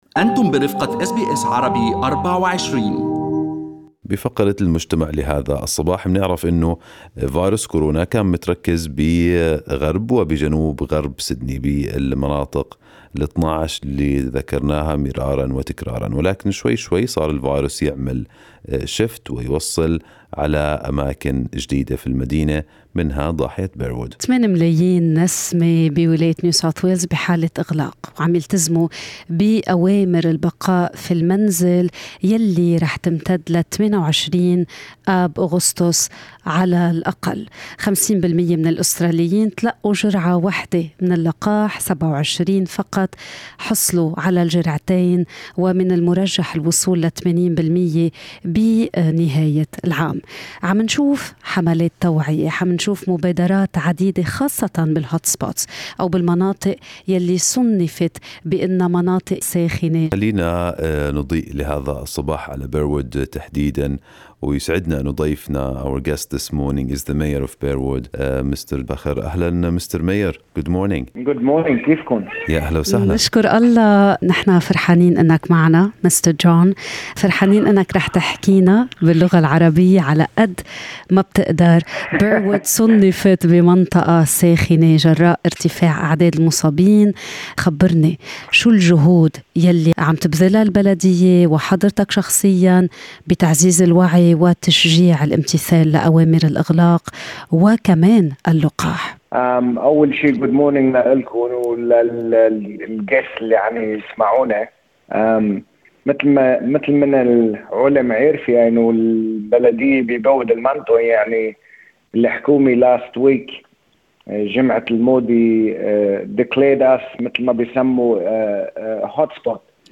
للإضاءة حول هذا الموضوع، استضاف برنامج Good morning Australia رئيس بلدية Burwood السيد جون فخر.